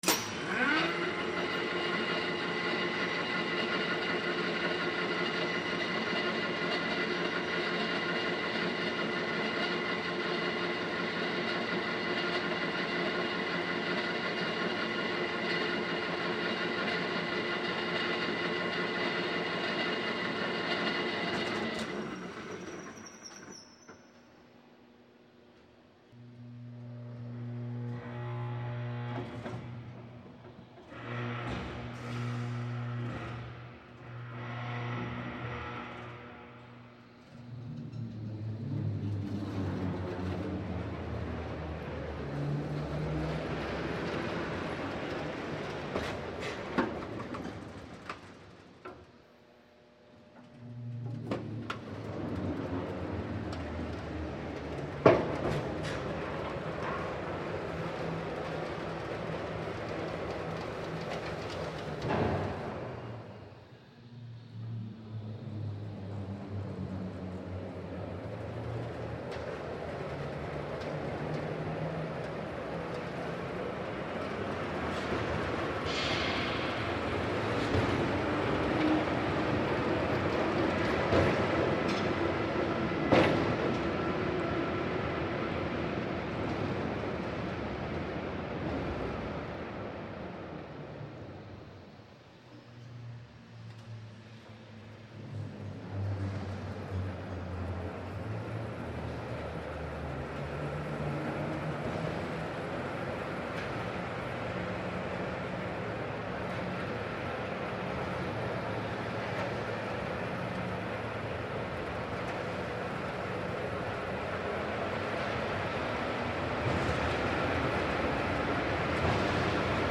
Шум работы подъемного крана